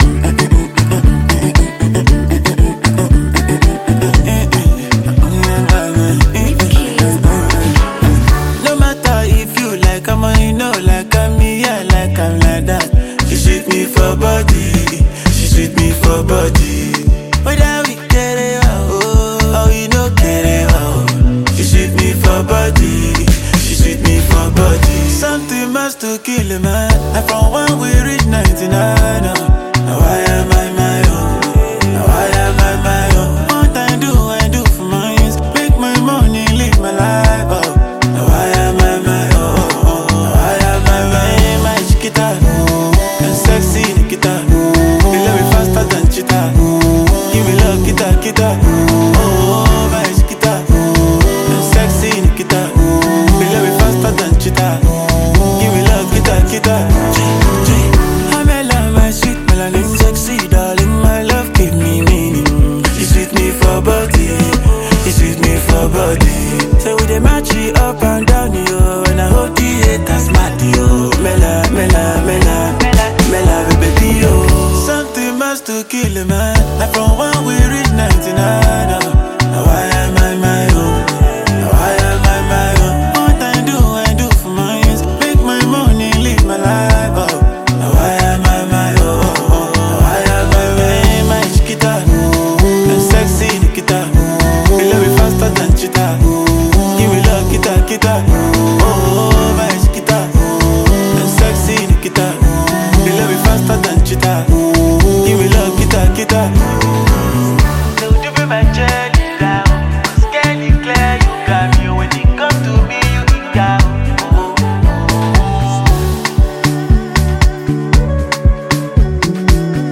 Afropop
signature melodic bounce and catchy hooks